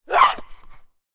femalezombie_spotted_02.ogg